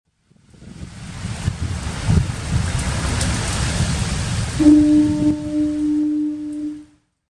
foghorn.mp3